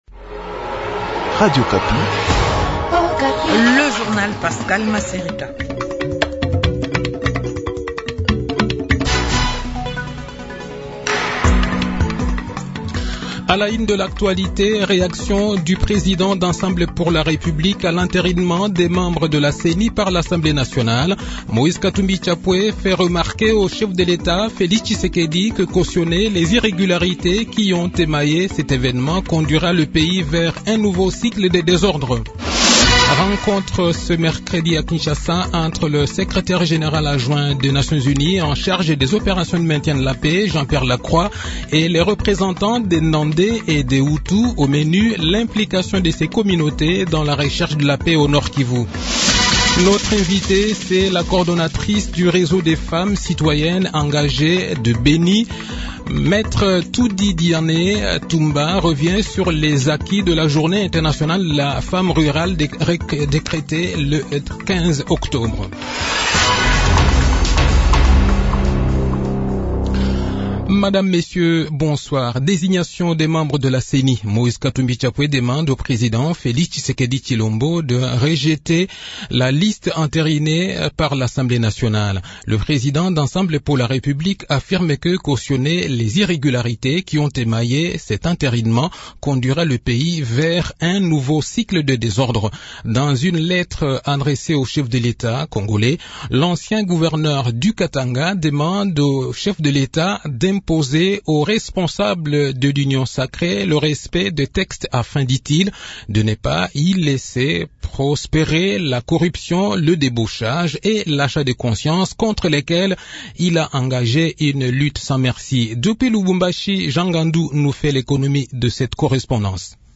Le journal de 18 h, 20 Octobre 2021